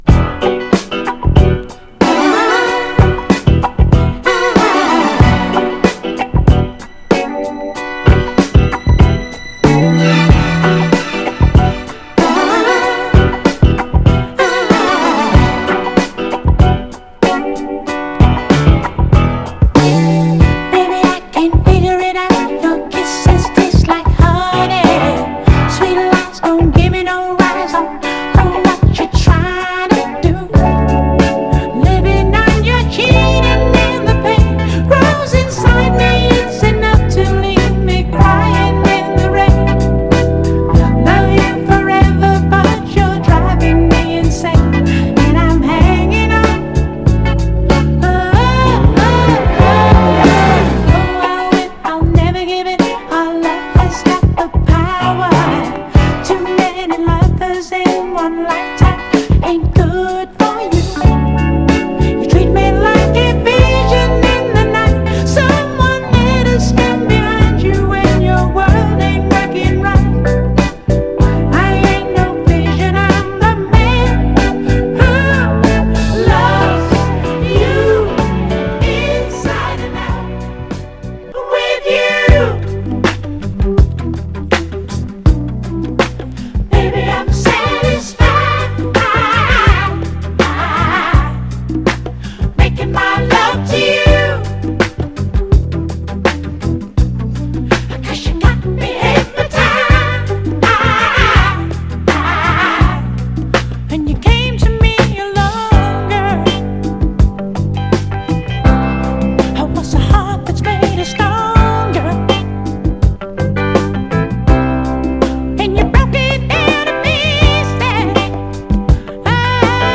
モダン・ステッパー仕様のR&B〜ブギー・スタイルでイケてます。
FORMAT 7"
※試聴音源は実際にお送りする商品から録音したものです※